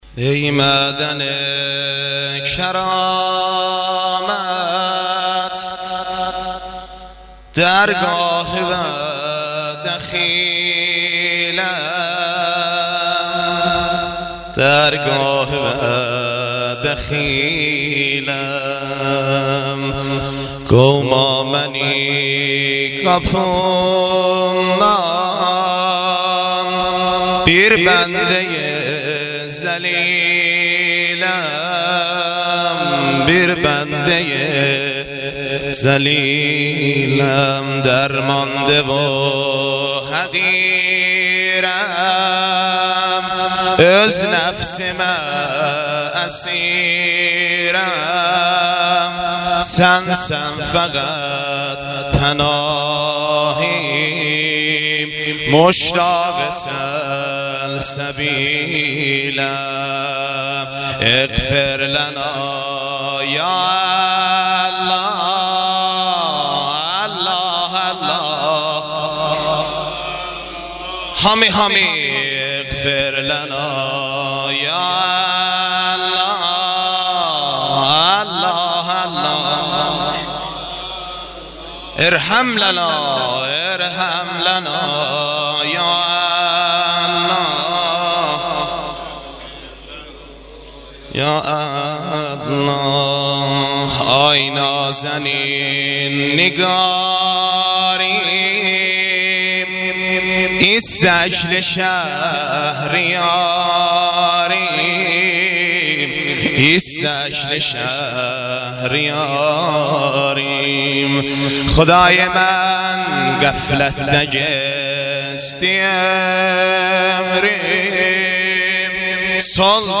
مناجات و روضه امام حسین (پیشواز رمضان)